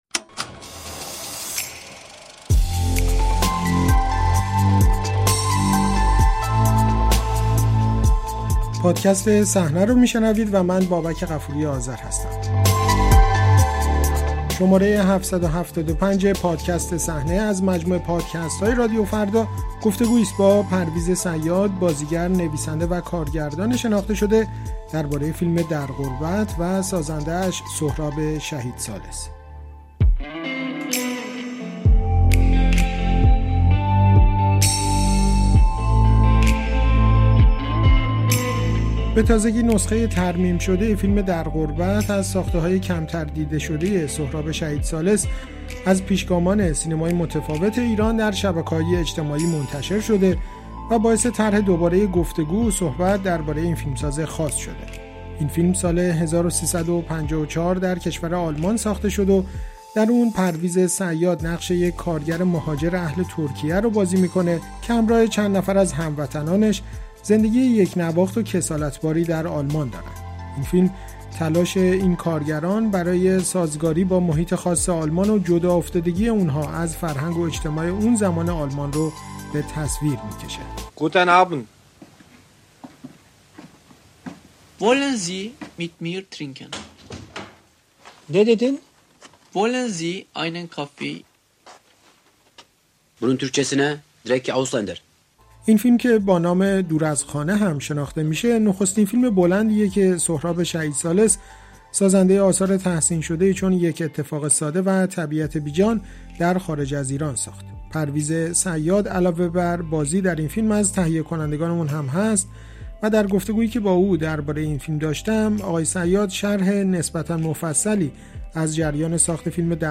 بازخوانی «در غربت» و سهراب شهیدثالث در گفت‌وگو با پرویز صیاد